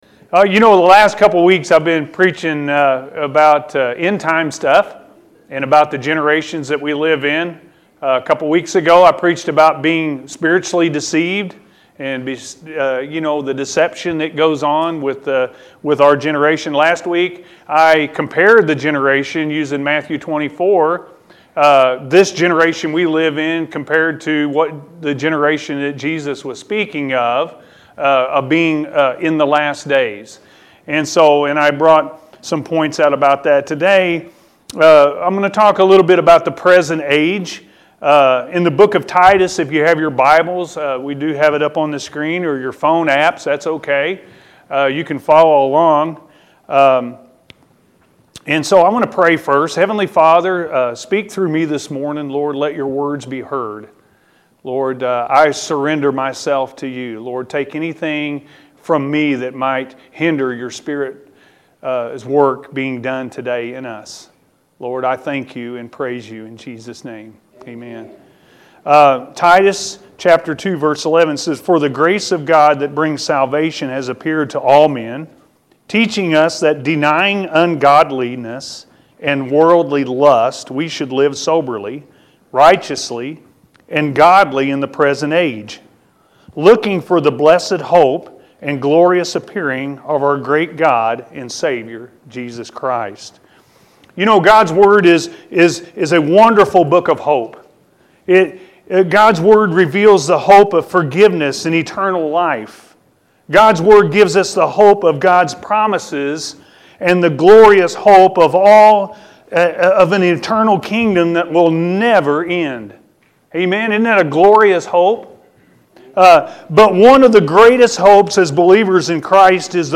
The Promise Of His Return-A.M. Service